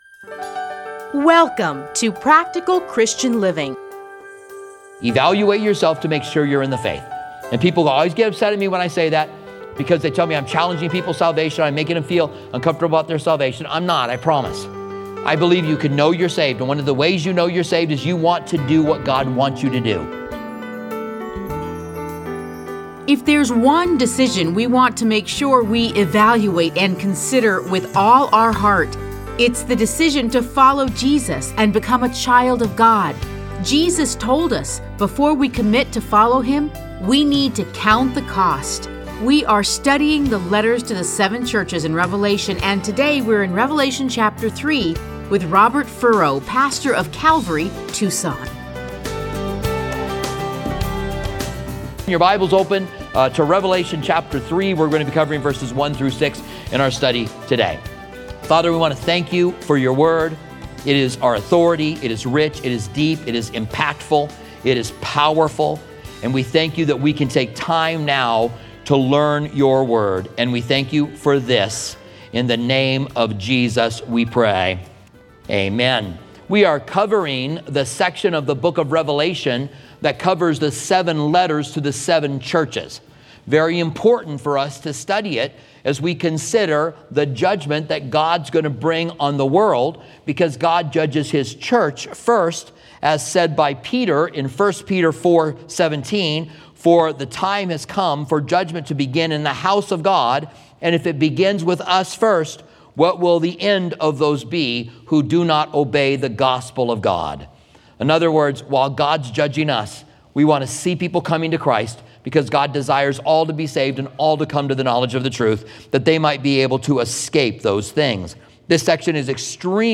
Listen to a teaching from Revelation 3:1-6.